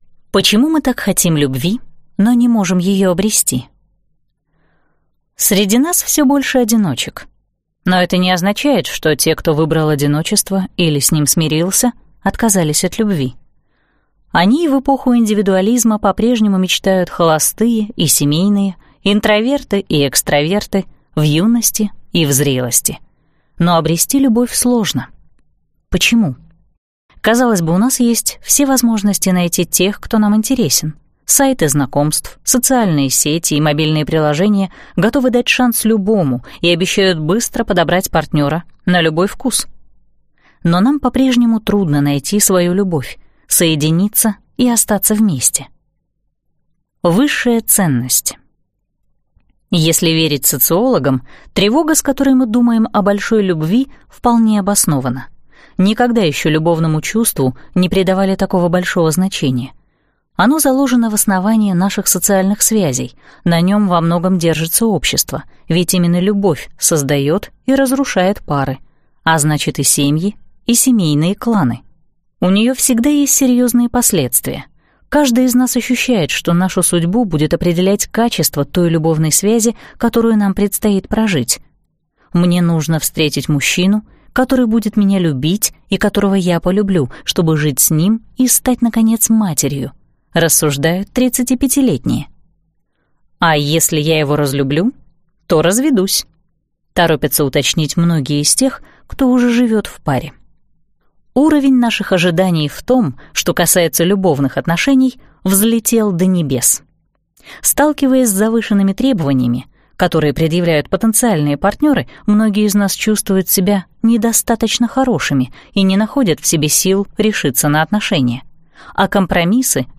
Аудиокнига Страх любви. Почему я одинока и что с этим делать?